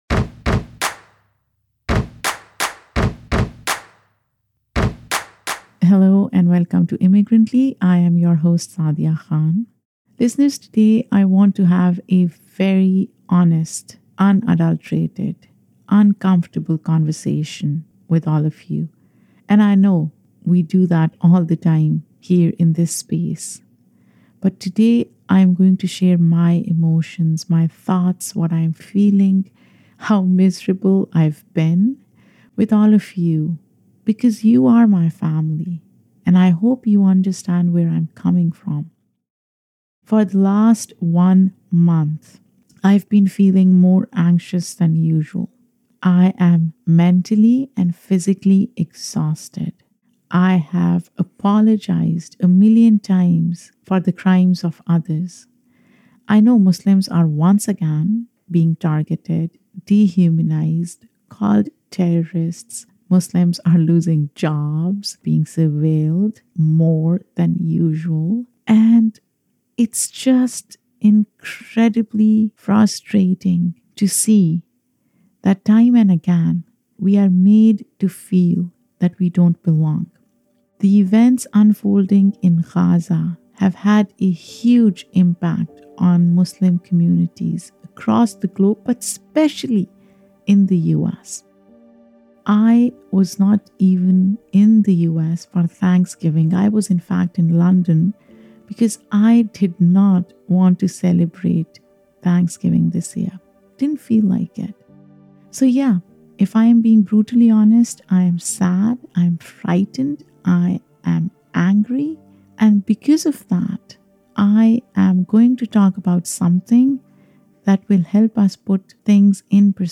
There’s no guest today; instead, I investigate the subtle nuances in our words through the deeply rooted history of “dog-whistling.”